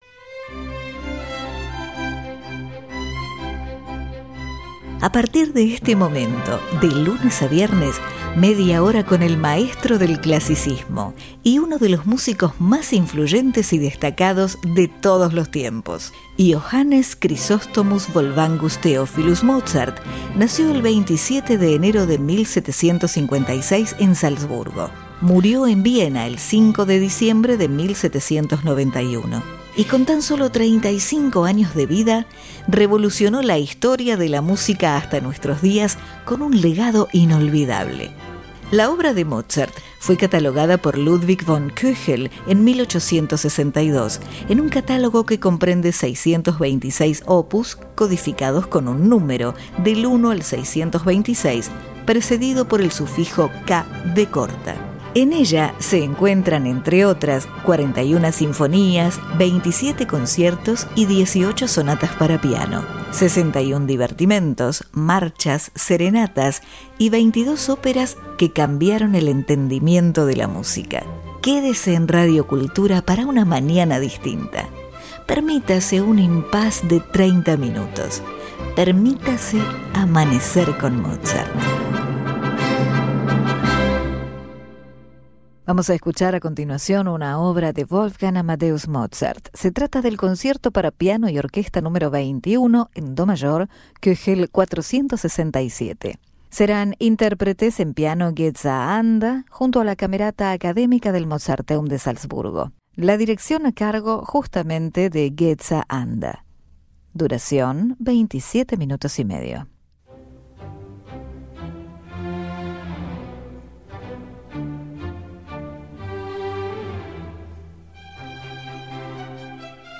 Concierto Para Piano Y Orquesta Nº 21 En Do Mayor K. 467 Geza Anda(Piano) Camerata Academica Del Mozarteum De Salzburgo